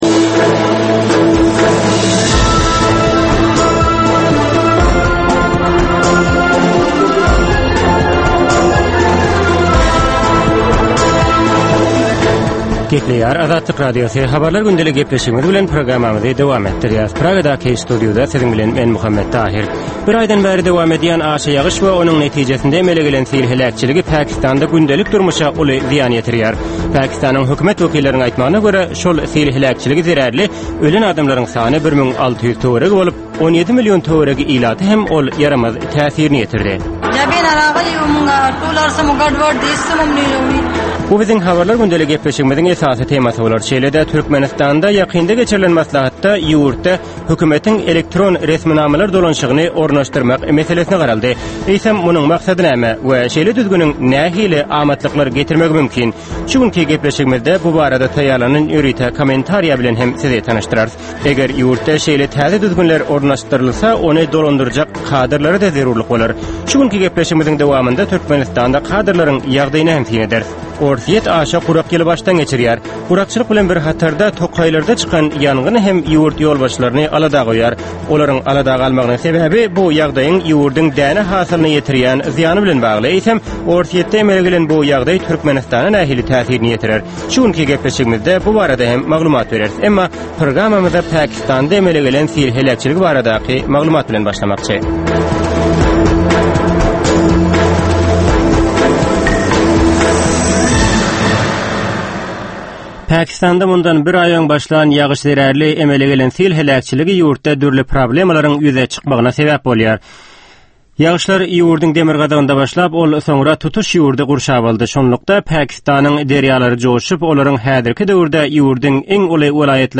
Türkmenistandaky we halkara arenasyndaky soňky möhüm wakalar we meseleler barada ýörite informasion-analitiki programma. Bu programmada soňky möhüm wakalar we meseleler barada giňişleýin maglumatlar, analizler, synlar, söhbetdeşlikler, kommentariýalar we diskussiýalar berilýär.